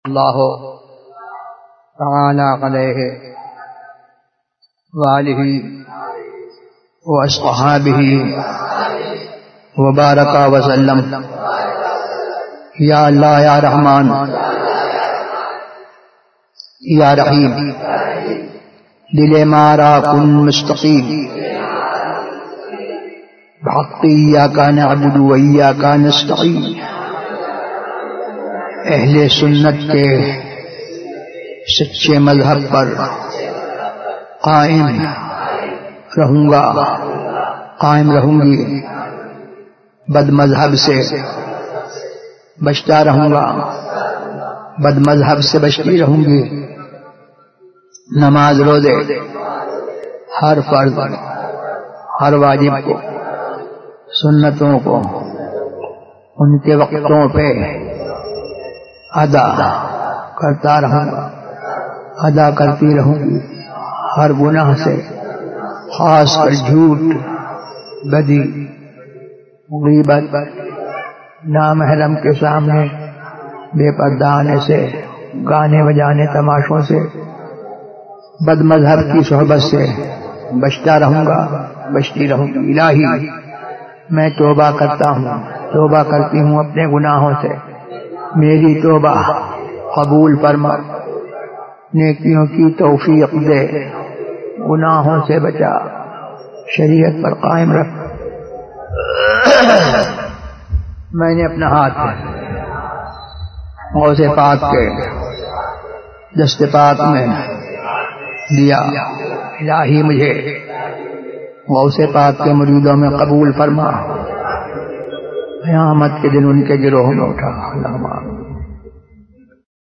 لاؤڈاسپیکر پر نماز کا حکم ZiaeTaiba Audio میڈیا کی معلومات نام لاؤڈاسپیکر پر نماز کا حکم موضوع تقاریر آواز تاج الشریعہ مفتی اختر رضا خان ازہری زبان اُردو کل نتائج 1110 قسم آڈیو ڈاؤن لوڈ MP 3 ڈاؤن لوڈ MP 4 متعلقہ تجویزوآراء